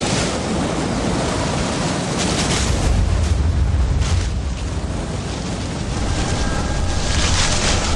• Winds gusting.ogg
[winds-gusting]_kdw.wav